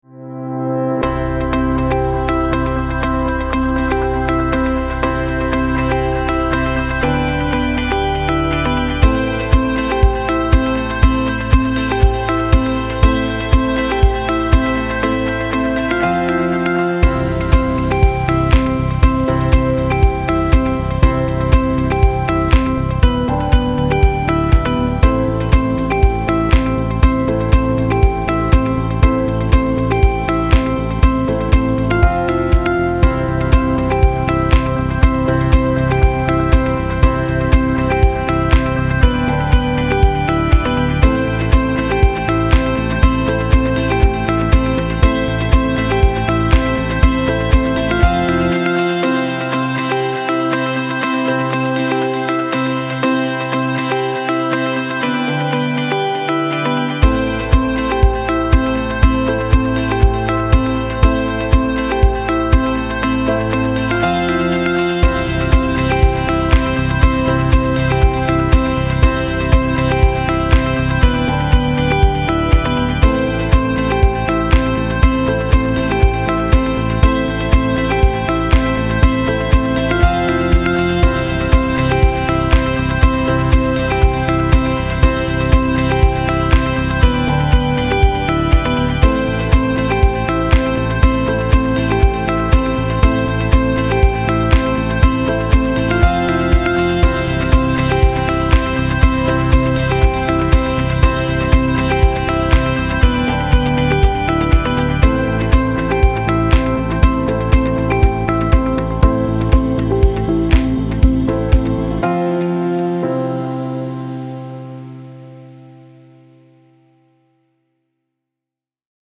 a cheerful learning song made for kids